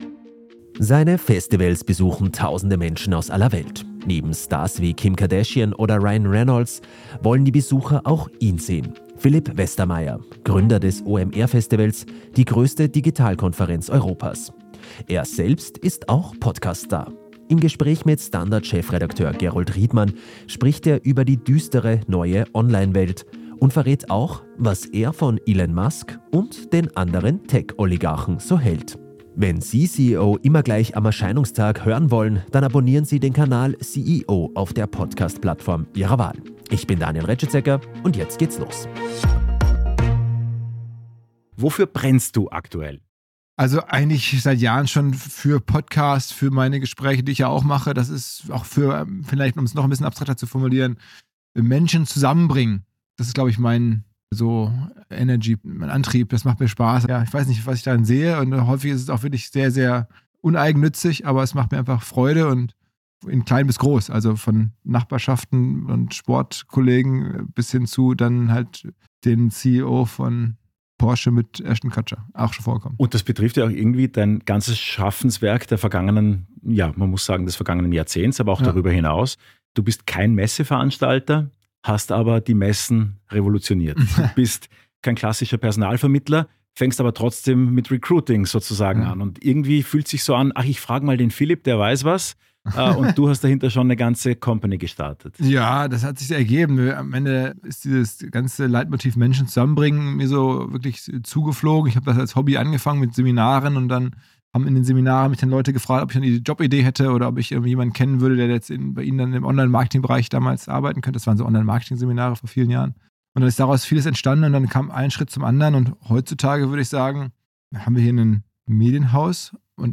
war bei ihm in Hamburg zu Gast